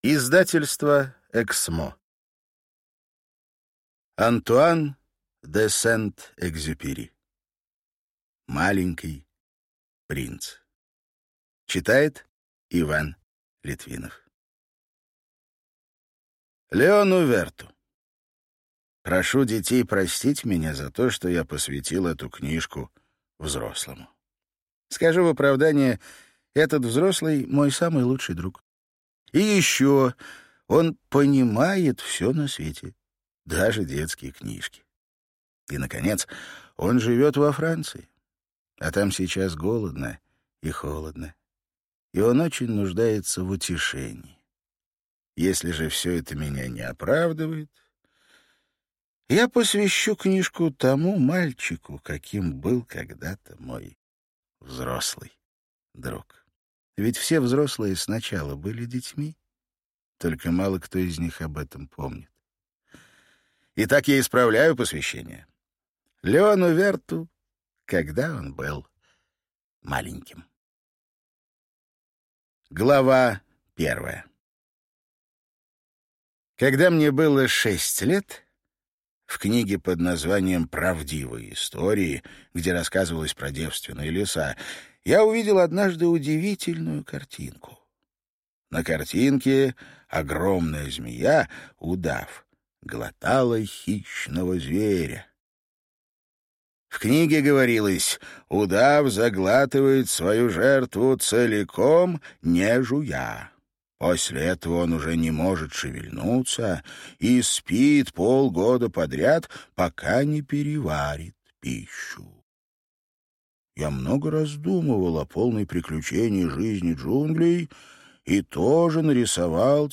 Аудиокнига Маленький принц | Библиотека аудиокниг